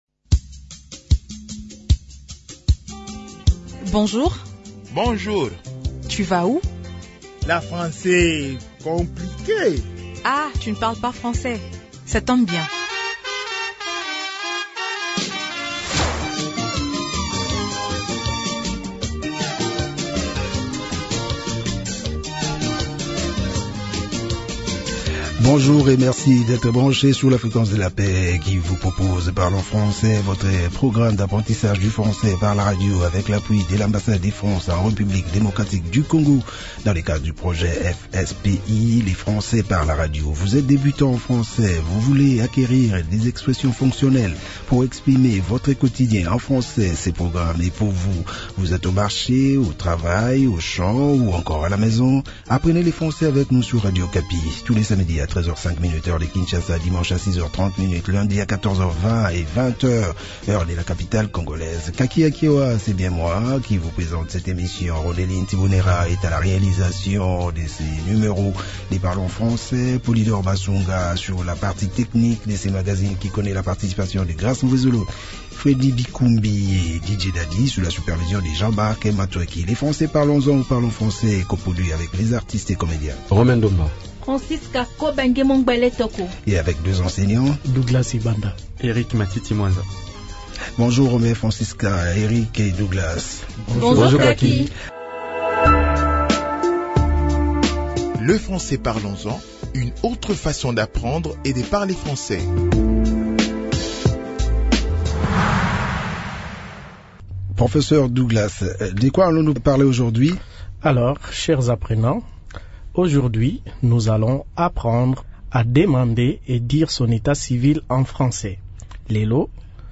Bienvenue à l’écoute de cette nouvelle leçon de notre programme "Parlons Français". Dans cet épisode, nous vous proposons des expressions simples pour parler de son état civil. Cette émission, destinée au public allophone, regroupe tous les éléments nécessaires pour apprendre aisément le français fonctionnel.